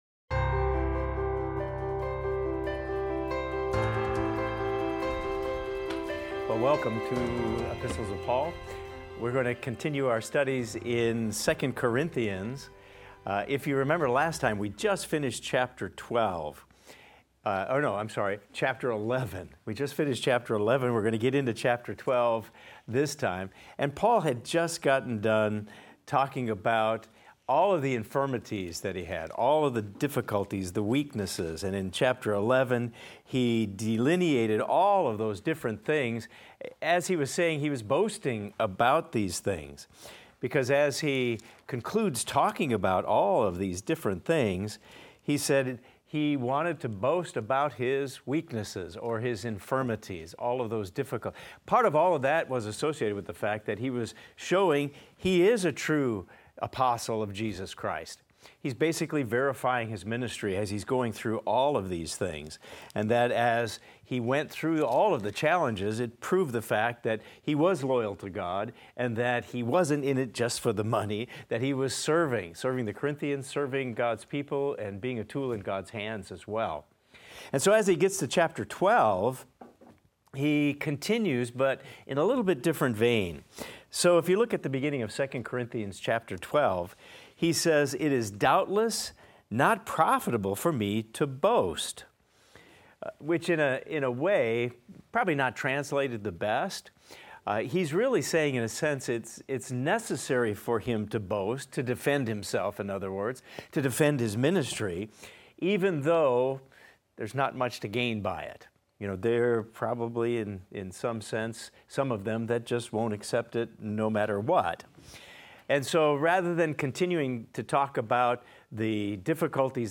In this class we will discuss 2 Corinthians 12:1–19 and examine the following: Paul describes a divine vision and revelation he experienced.